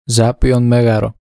The Zappeion (Greek: Ζάππειον Μέγαρο, romanizedZáppeion Mégaro, pronounced [ˈzapi.on ˈmeɣaro]
Pronunciation_of_Zappeio.ogg.mp3